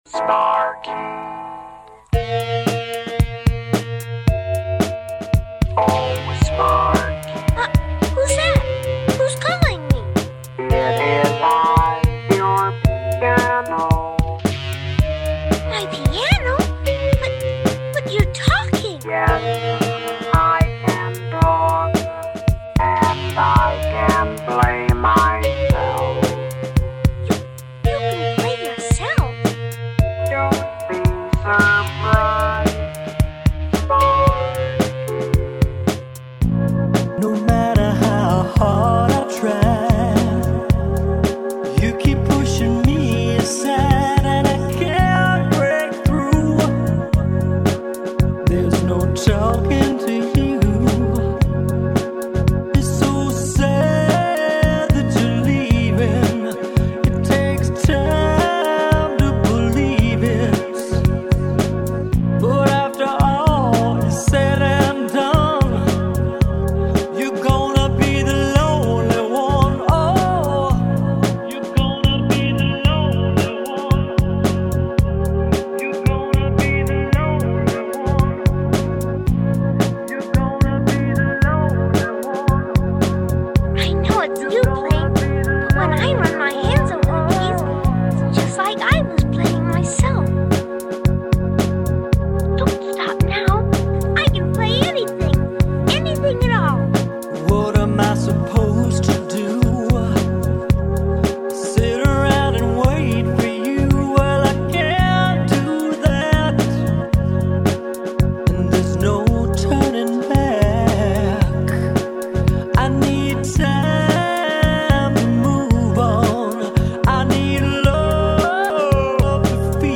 He makes music using samples.
Here is an mp3 that's one of my favourites: a spooky Cher, Eels, talking piano mix called